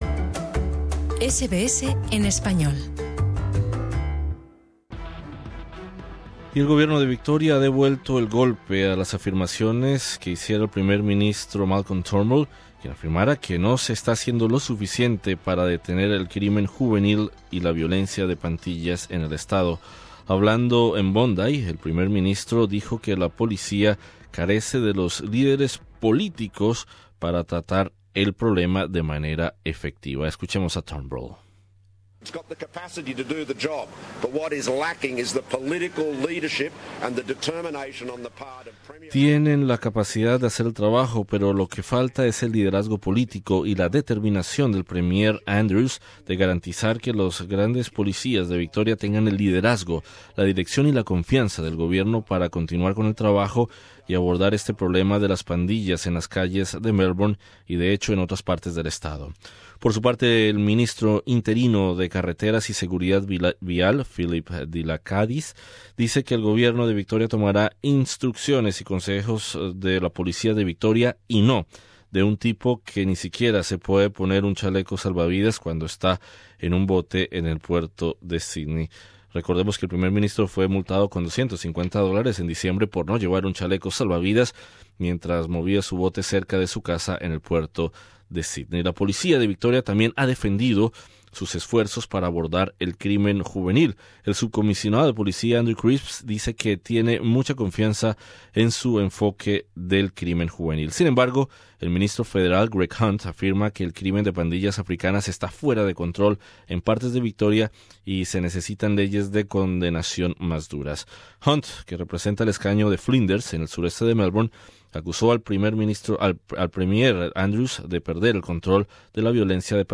Entrevistamos al diputado laborista por el escaño de Tarneit, Telmo Languiller, quien nos habla sobre la situación en estas zona del Estado de Victoria.